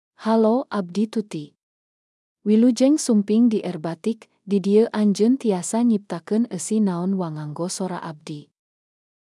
Tuti — Female Sundanese (Indonesia) AI Voice | TTS, Voice Cloning & Video | Verbatik AI
Tuti is a female AI voice for Sundanese (Indonesia).
Voice sample
Listen to Tuti's female Sundanese voice.
Female
Tuti delivers clear pronunciation with authentic Indonesia Sundanese intonation, making your content sound professionally produced.